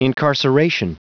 Prononciation du mot incarceration en anglais (fichier audio)
Prononciation du mot : incarceration